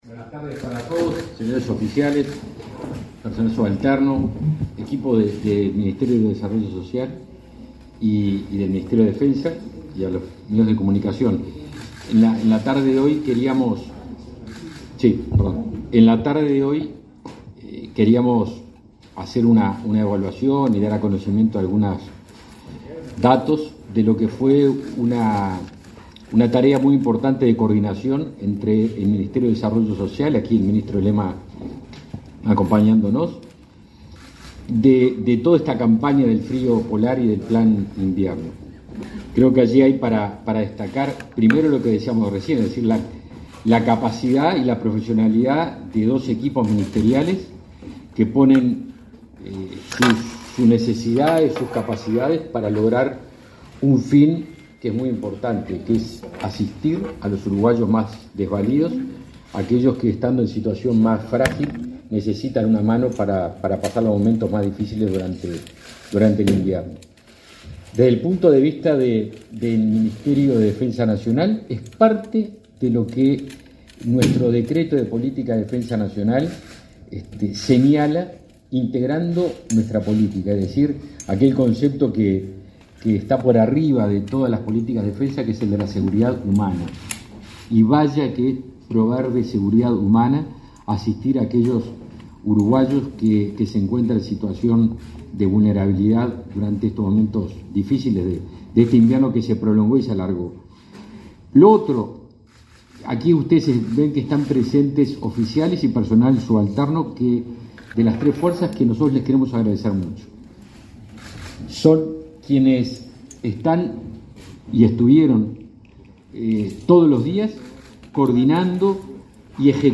Conferencia de prensa por resultados del Plan Invierno 2022
Este 4 de noviembre, los ministros de Defensa Nacional, Javier García, y de Desarrollo Social, Martín Lema, informaron, en conferencia de prensa,